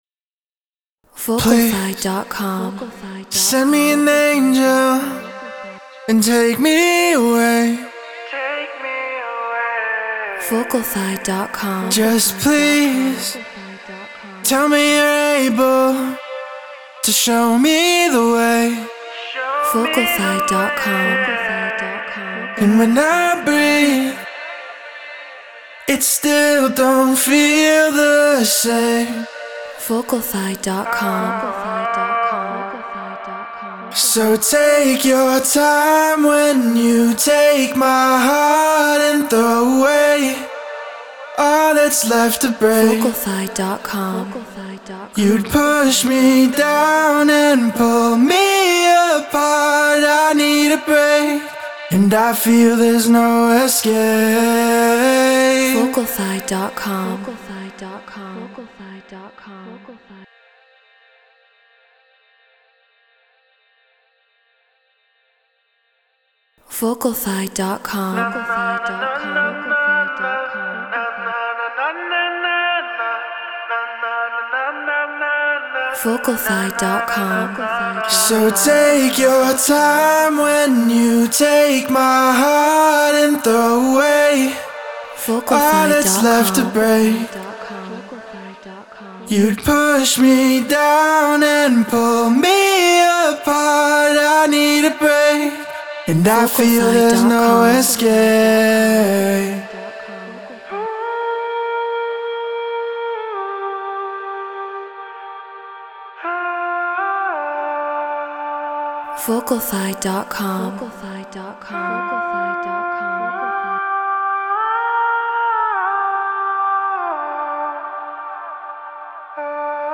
Drum & Bass 174 BPM Gmin
Human-Made